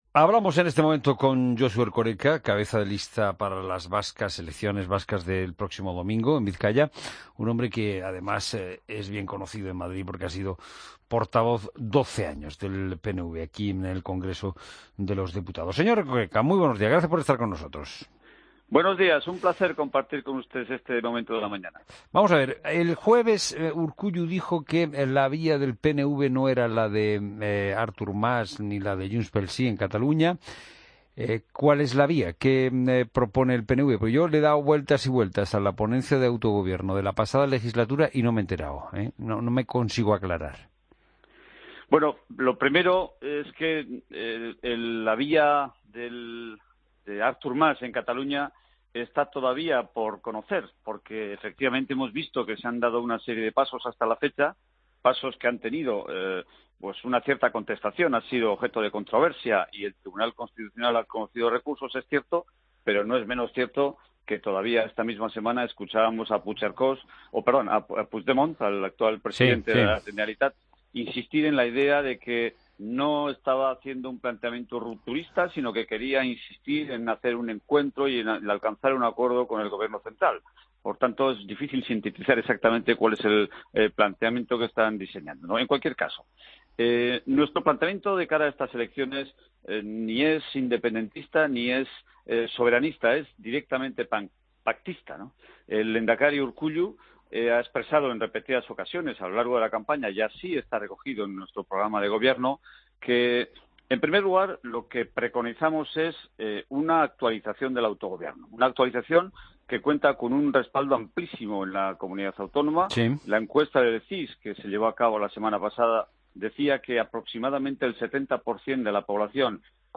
AUDIO: Escucha la entrevista al portavoz del PNV, Josu Erkoreka, en La Mañana de Fin de Semana